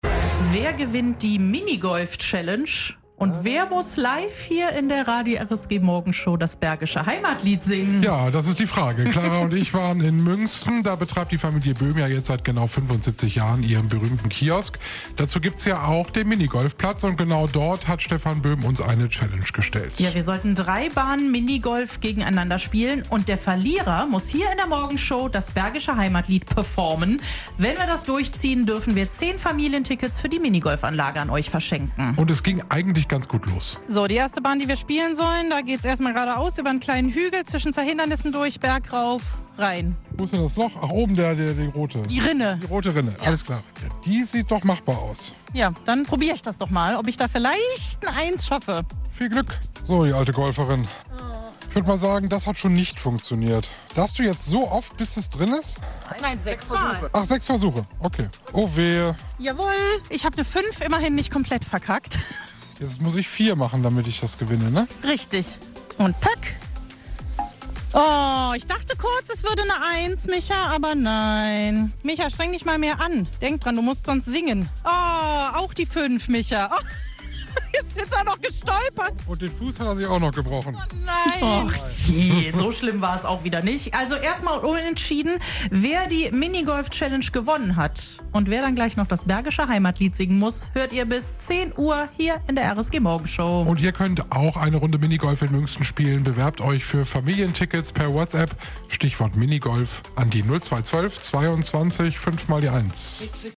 Radio RSG vor Ort: Minigolf-Challenge zum Jubiläum